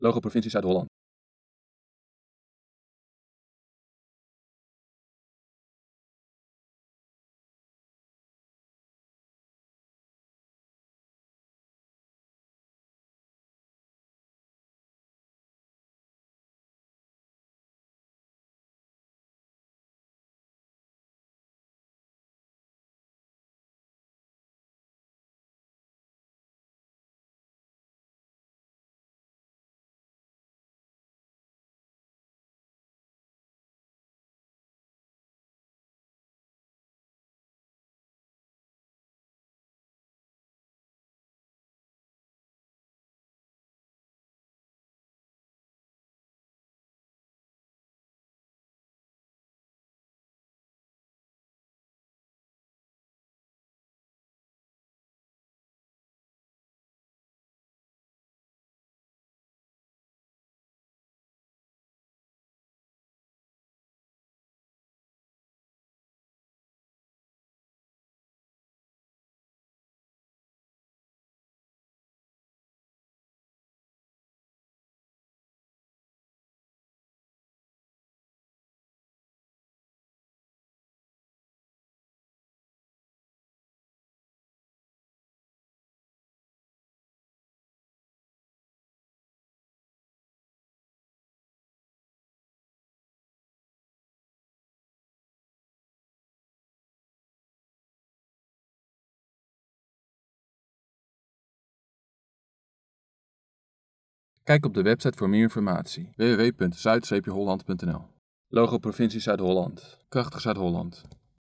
pzh-gebiedsprocessen-audiodescriptie-v01.mp3